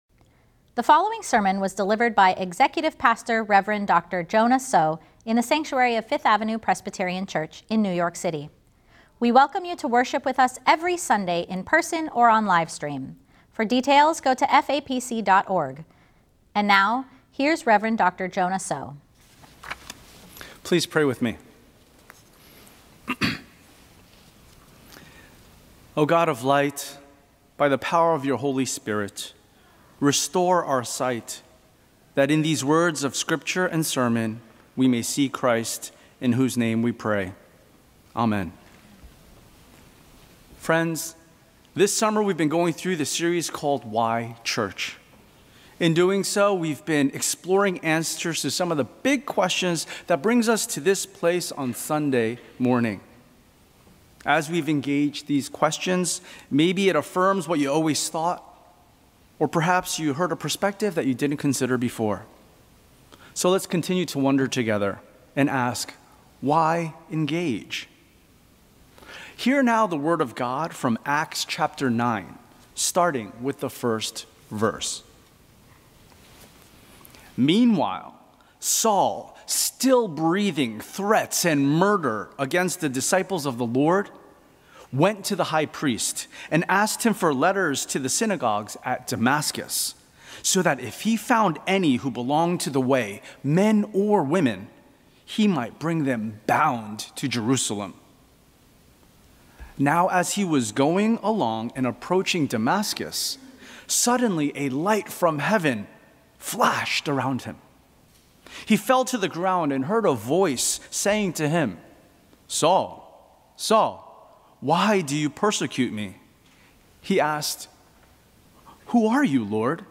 Sermon: “Why Engage?” Scripture: Acts 9:1-19